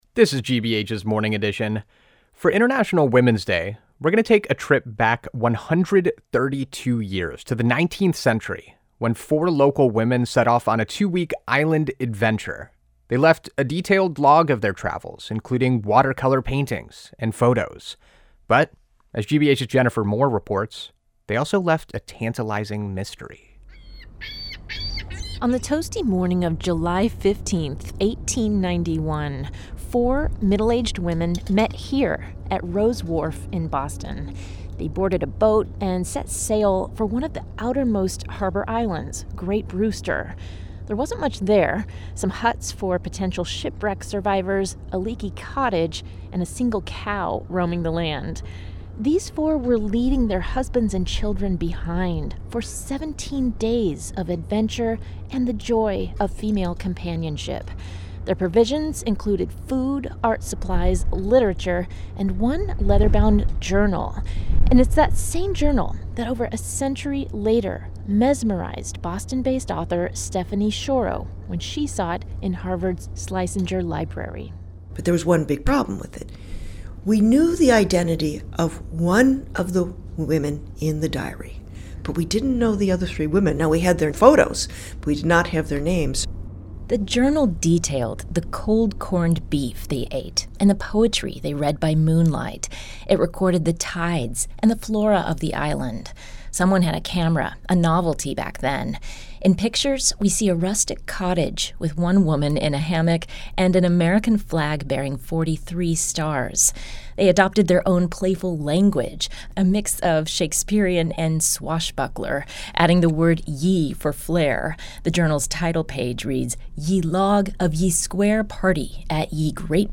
WGBH Interview